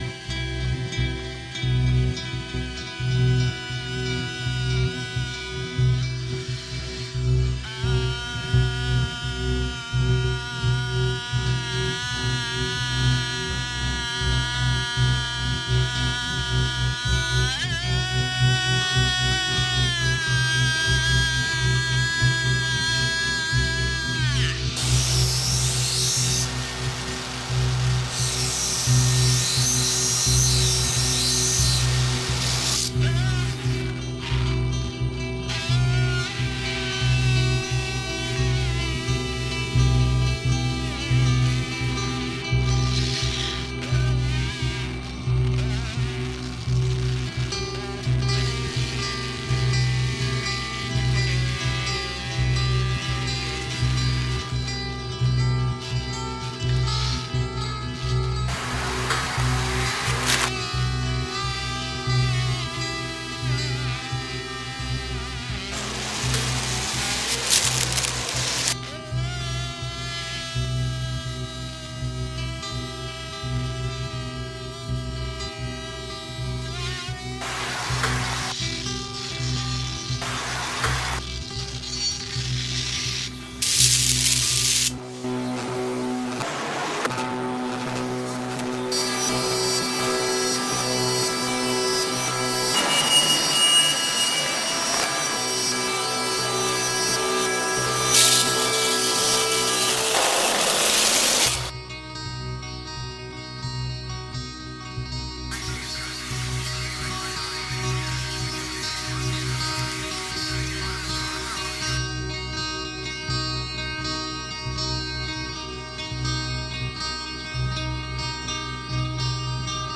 • Genre: Experimental / Electroacoustic